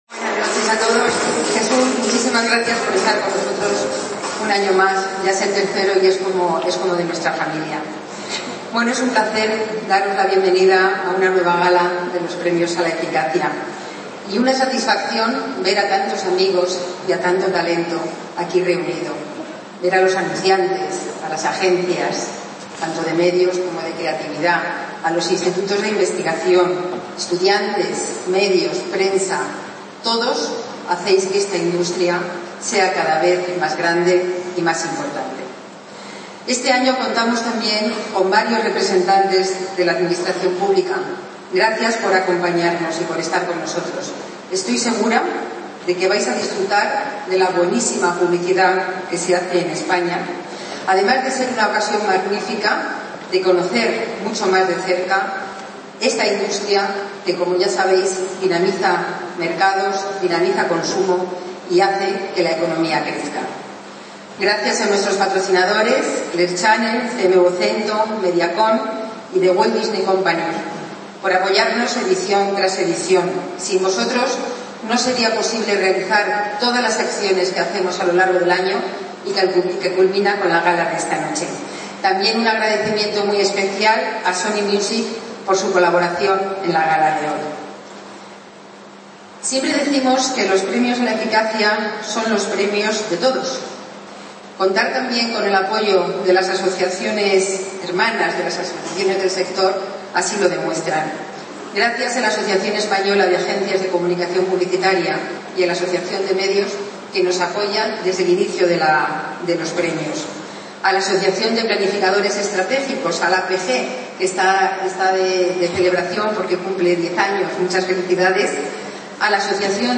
Convocados por la Asociación Española de Anunciantes, el Teatro Real de Madrid volvió a acoger la Gala de los Premios a la Eficacia, que ya va por su XVIII edición.
Discurso de Bienvenida